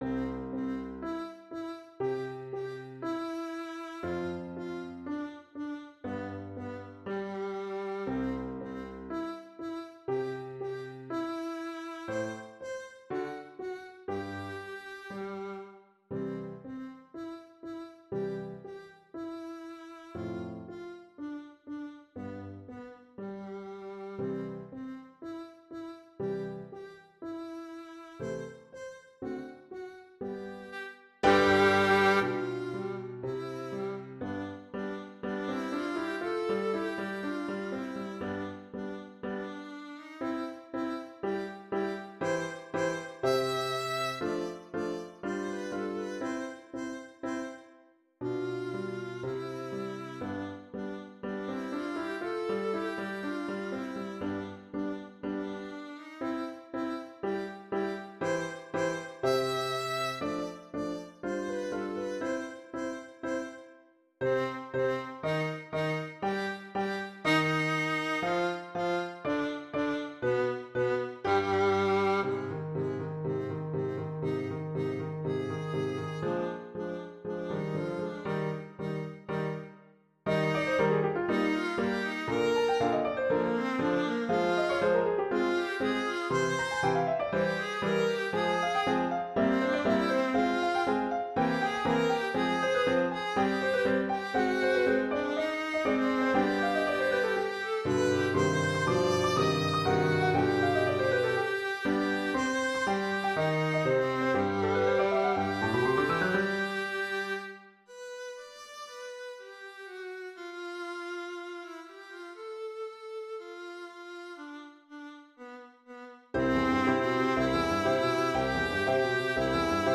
Viola and piano duet